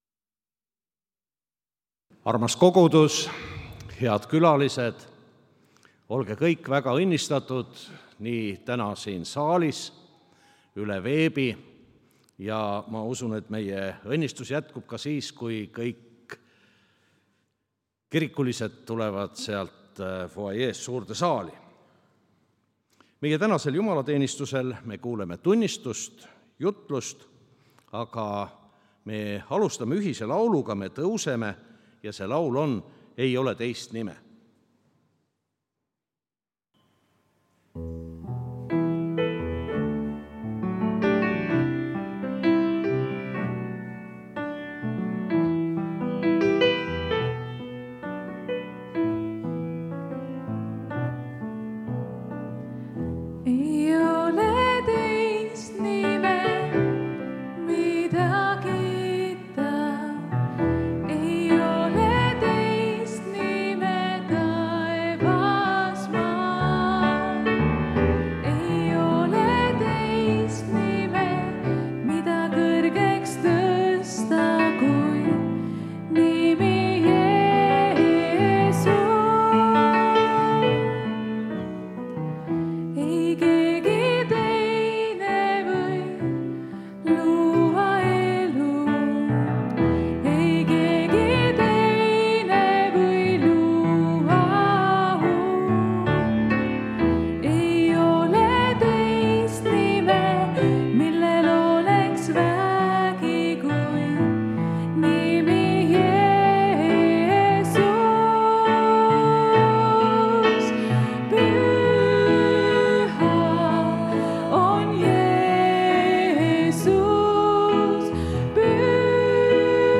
Kõik jutlused